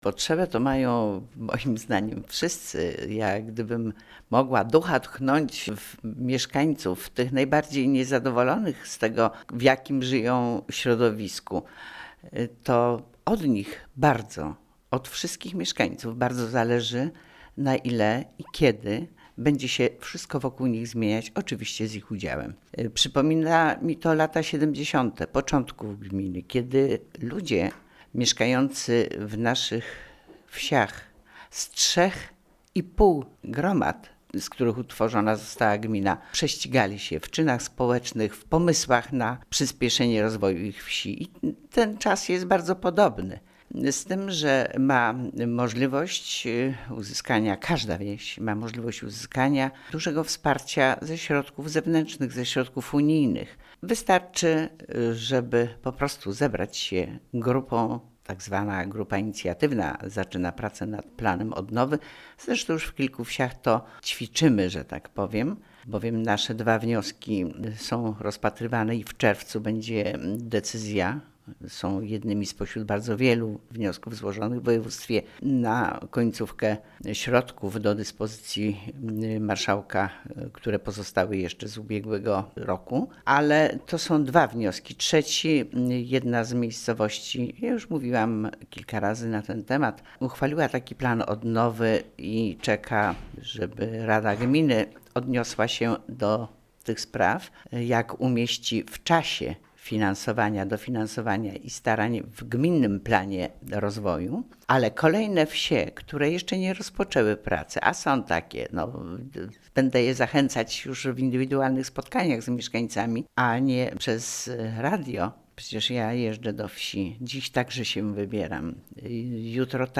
Wójt Gminy Łuków